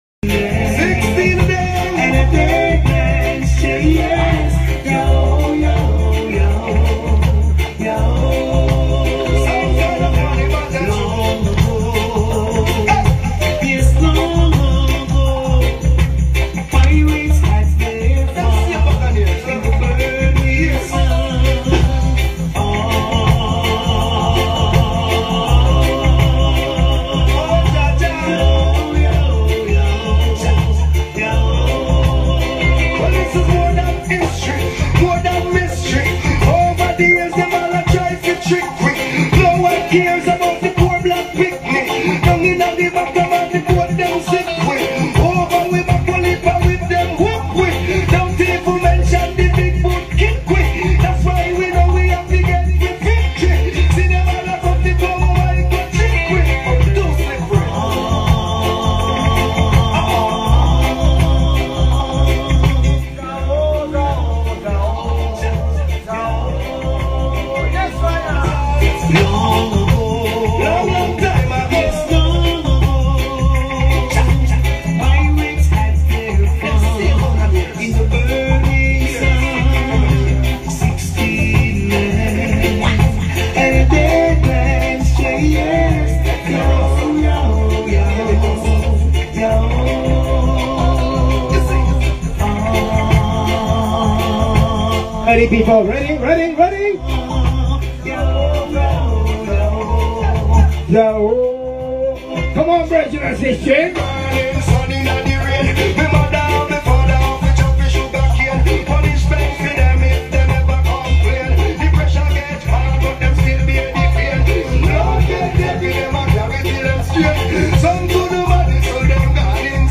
Japan Tour in Yamagata,JP.
Live Recording